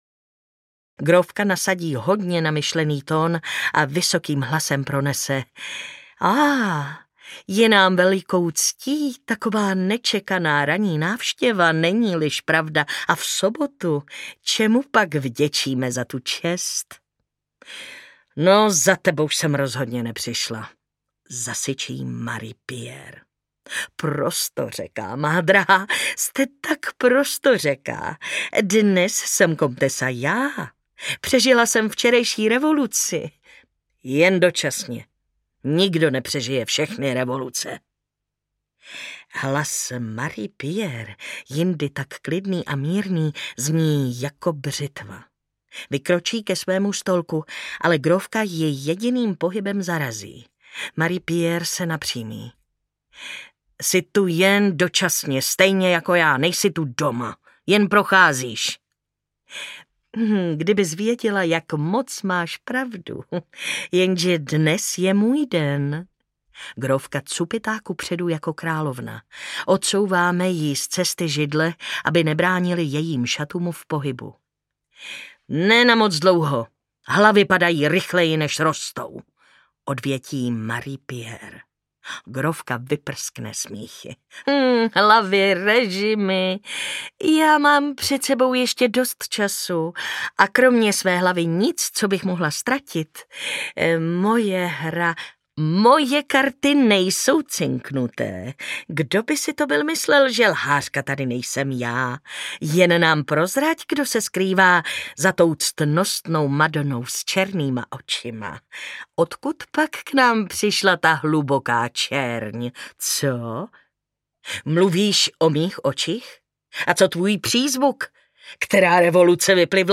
Grófka audiokniha
Ukázka z knihy
Čte Martina Hudečková.
Vyrobilo studio Soundguru.
grofka-audiokniha